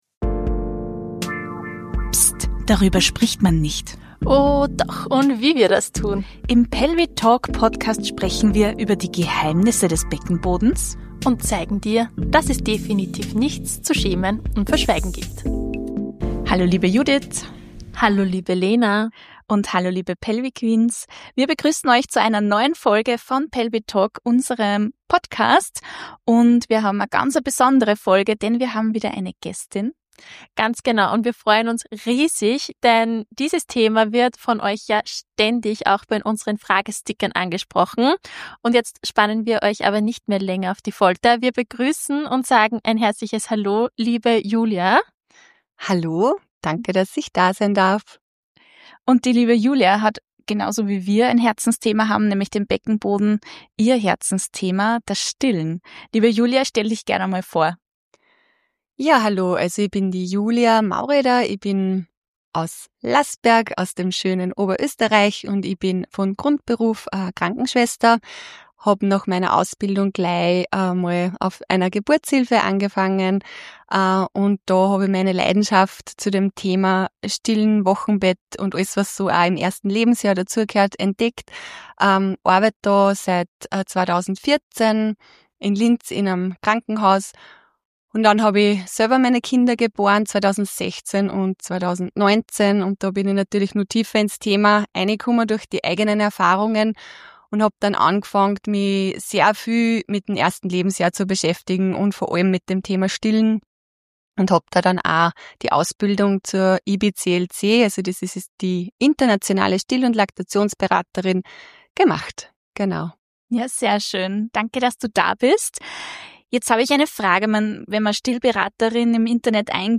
51_Stillen – Expertin spricht Klartext ~ PelviTalk Podcast